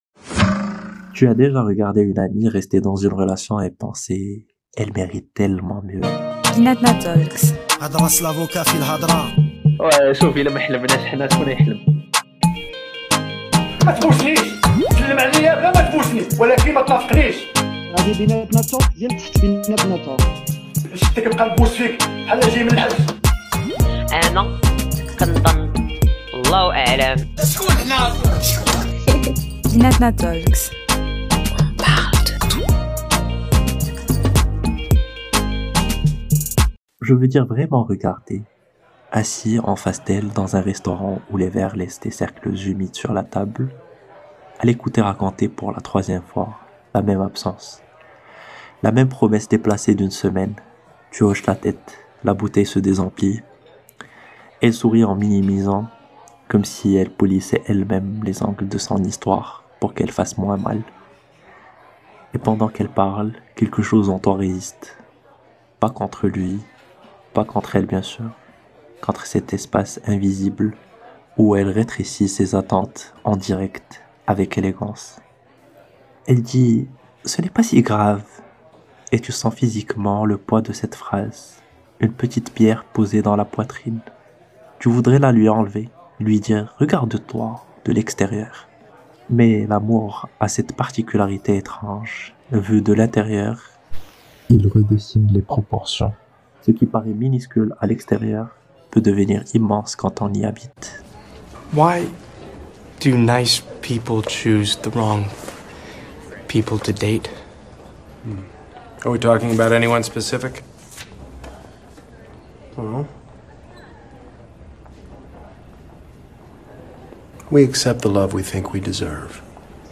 Autour d’une table, les voix se frôlent et l’amour se raconte à demi-mot. Une conversation intime, suspendue entre confidences et silences, les phrases rapprochent un peu plus du cœur des choses. Écoutez ce qui se dit quand on ose parler d’amour. celui qu’on mérite.